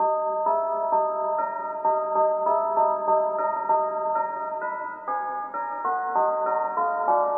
JuiceWRLD型钢琴 远
Tag: 126 bpm Trap Loops Piano Loops 2.56 MB wav Key : A